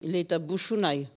Collectif patois et dariolage
Locution